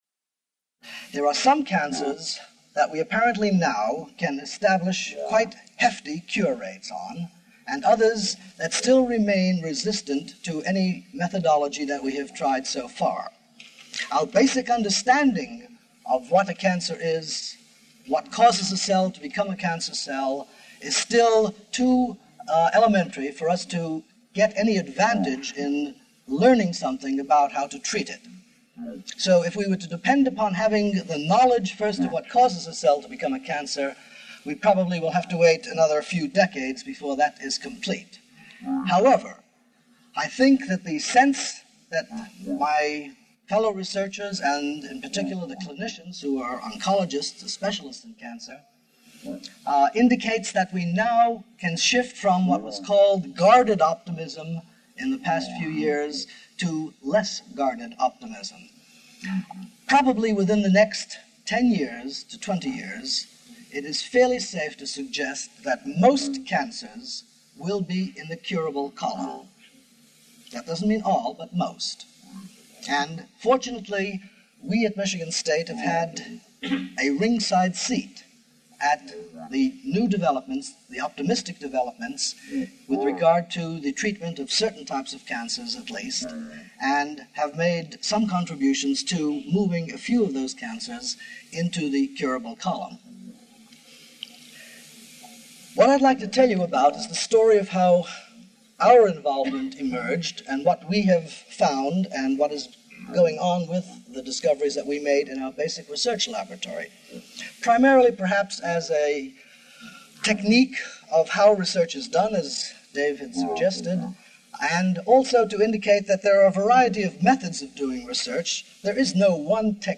Barnett Rosenberg talks about chemotherapeutic cures for certain cancers as part of the Great Teachers series in the MSU Evening College